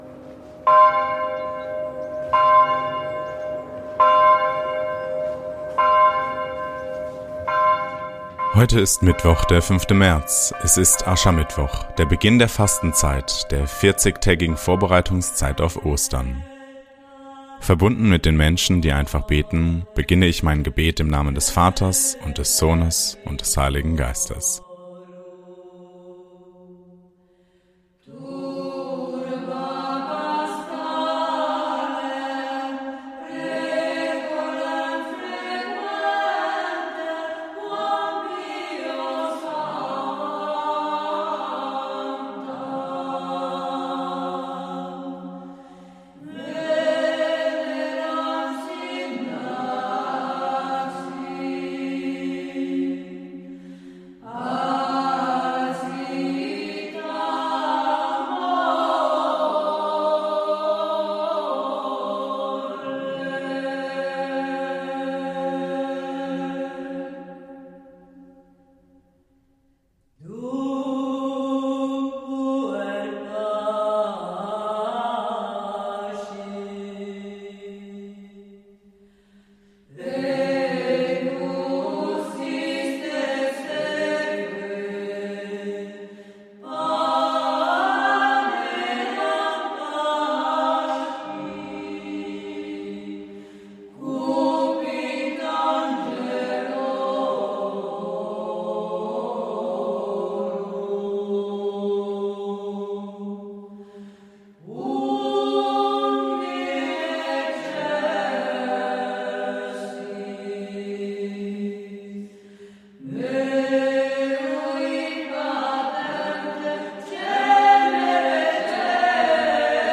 Lesung Mt 6, 1-6.16-18